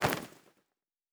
Bag 02.wav